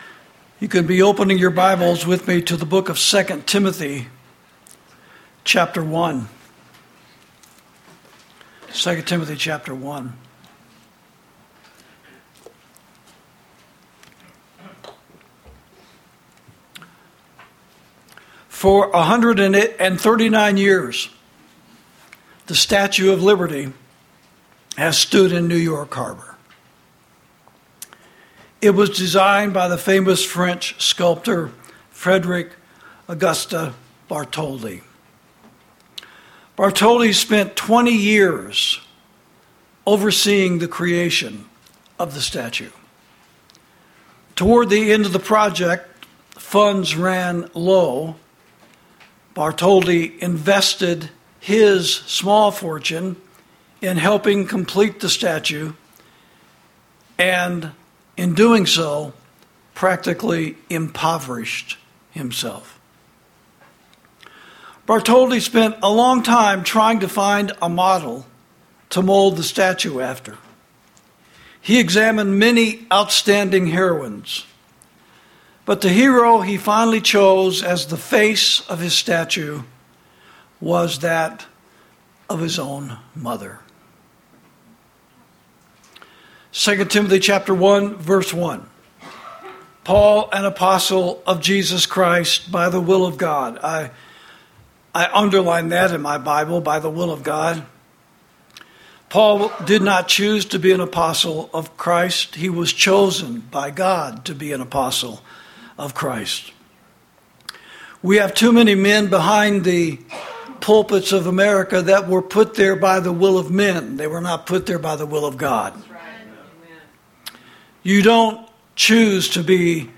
Sermons > Christian Motherhood: Our Country's Greatest Possession